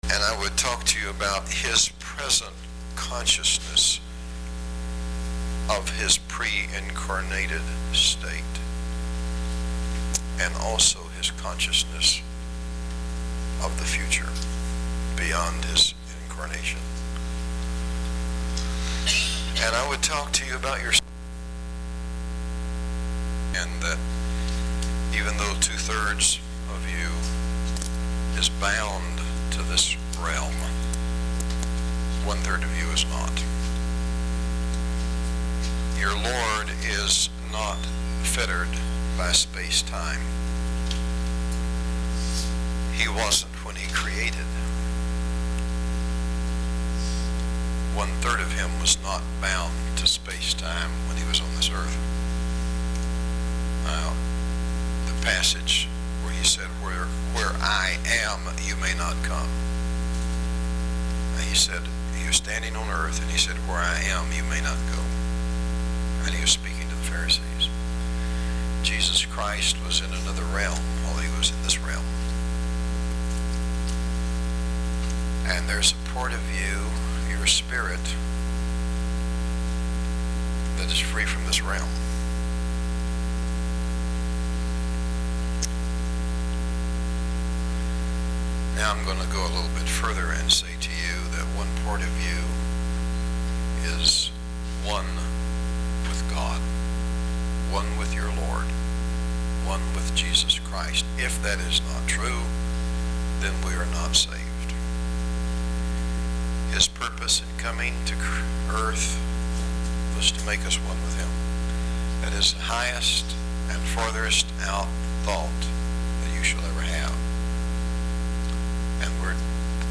Atlanta 1998 Conference – Re-thinking the Lord’s Prayer Part 2